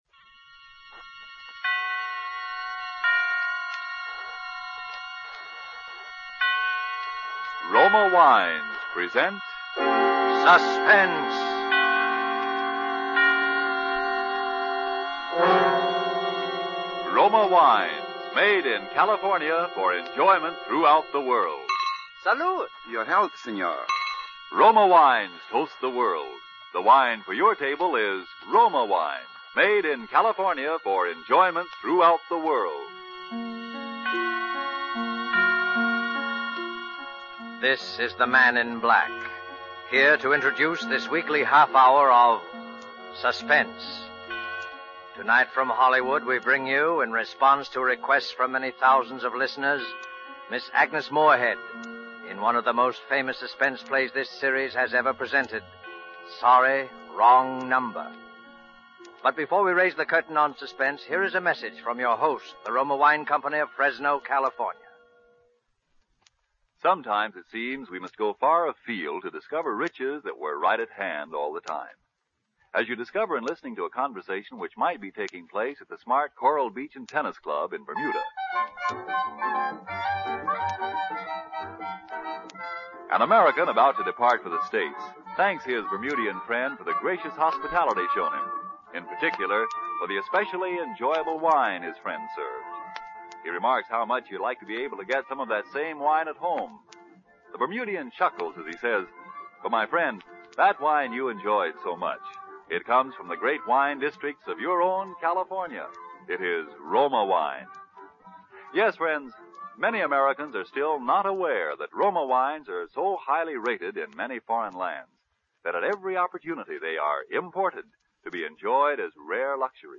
Fletcher's play originally aired on a radio program on May 25, 1943, essentially a one-woman show with Agnes Moorehead as Mrs. Stevenson.
radioplay.mp3